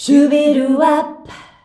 SHUBIWAP D.wav